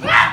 Added Wallaby identification sound